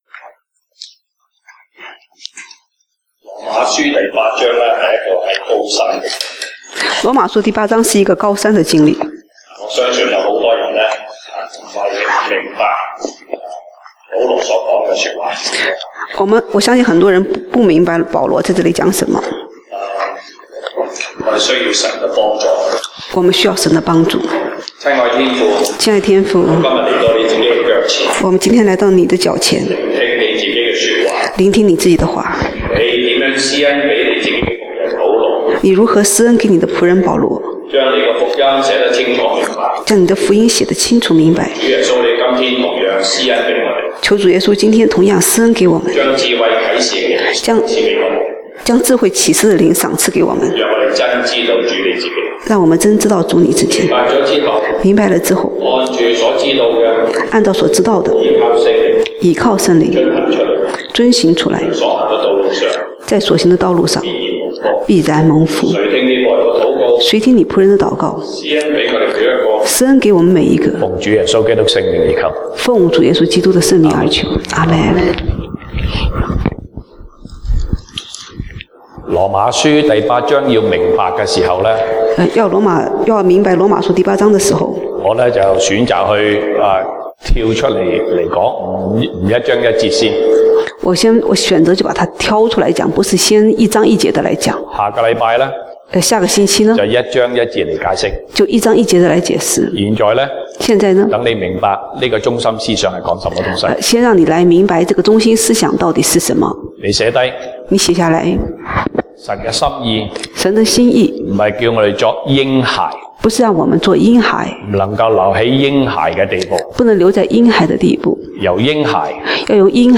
西堂證道(粵語/國語) Sunday Service Chinese: 高山的經歷